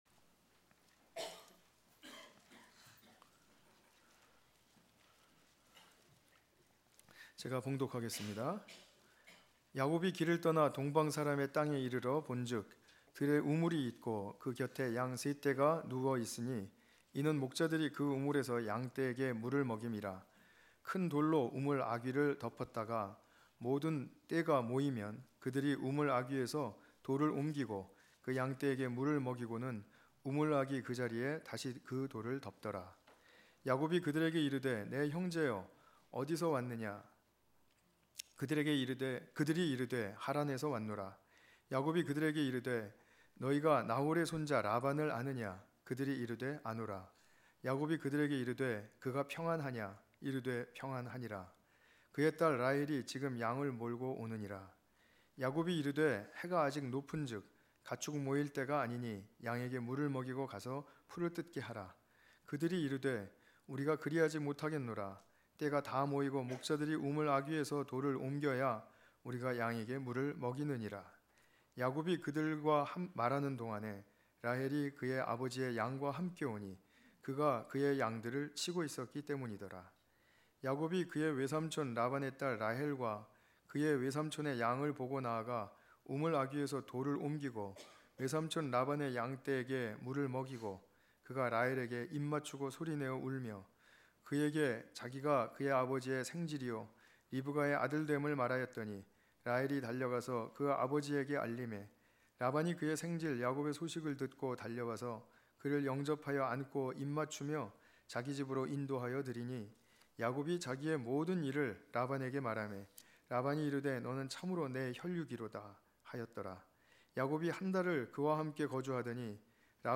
창세기 29장 1~20절 관련 Tagged with 주일예배